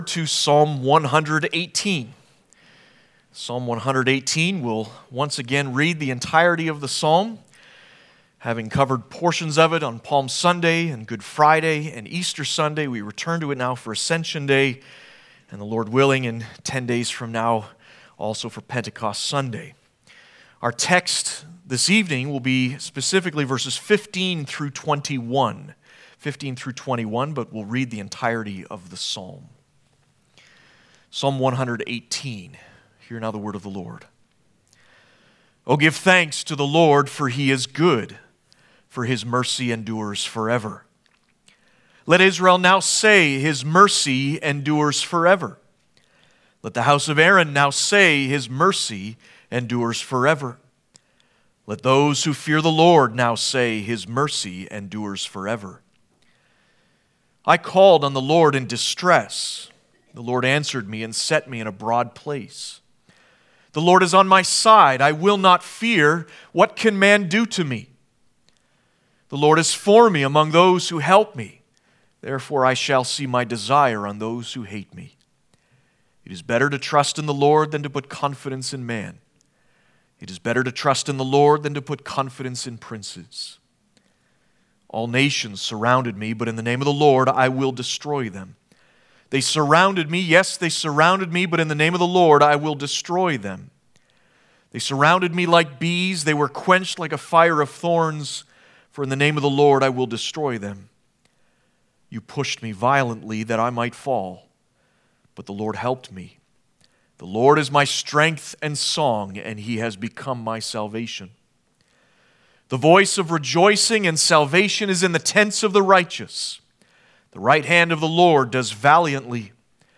Passage: Psalms 118:15-21 Service Type: Ascension Day